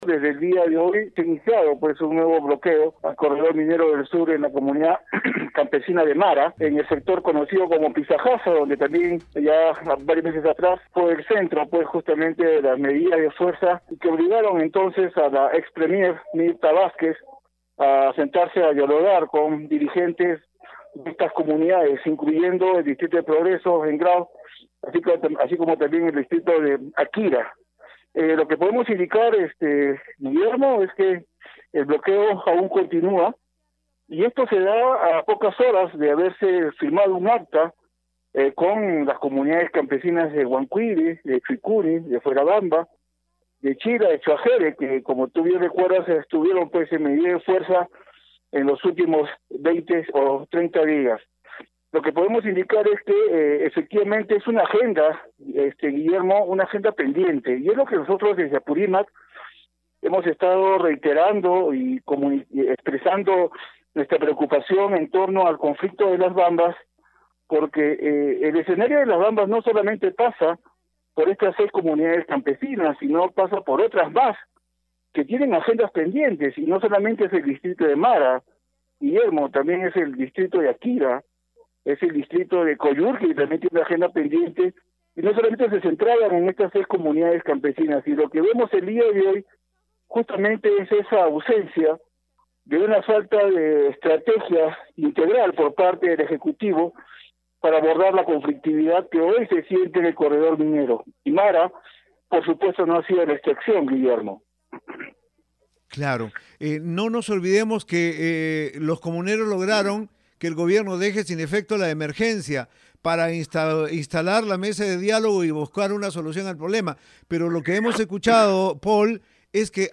«Ellos (comuneros) han señalado de que sí pues podrán ser las declaraciones de este diplomático chino, pero hay compromisos y acuerdos que aún no han sido cumplidos y eso no es retroactivo», informó el hombre de prensa.